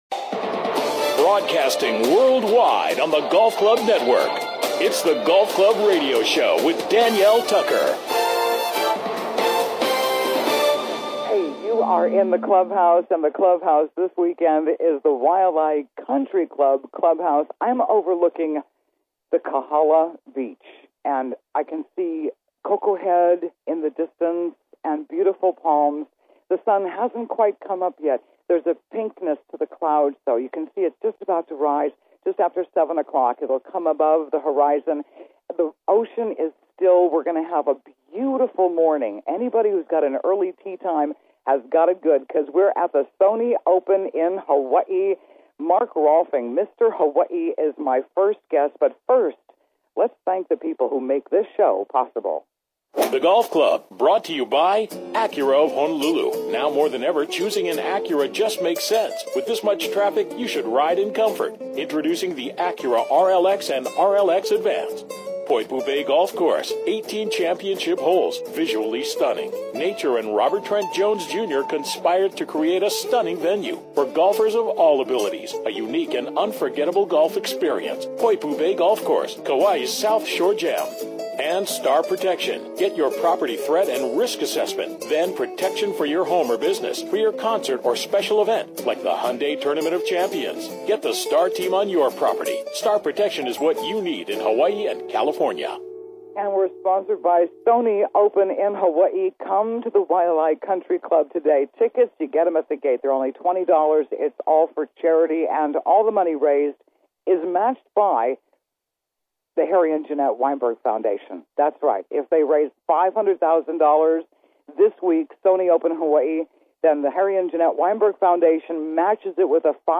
In the Clubhouse: Live from The Sony Open in Hawaii The Waialae Country Club, Honolulu, Hawaii